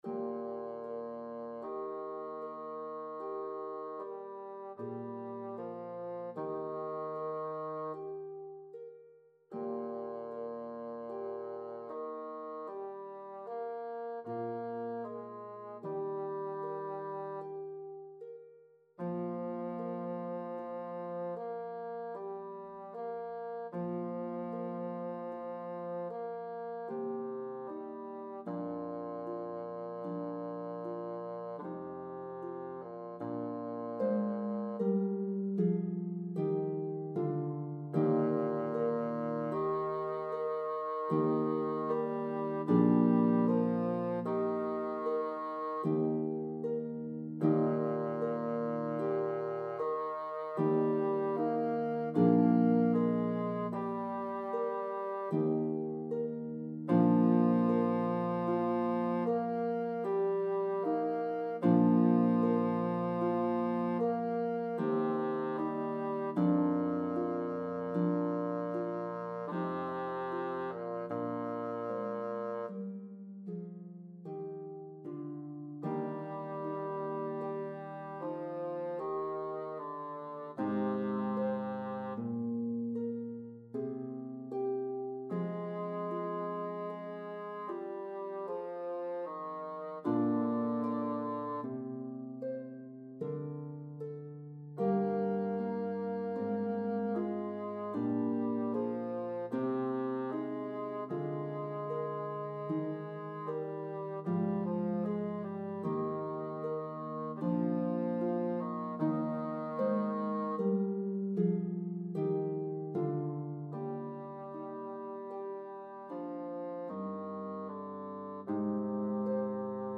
The orchestra parts play beautifully on a pedal harp.